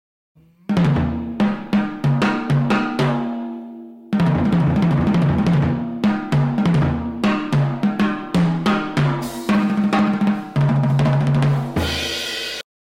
drum intro
drums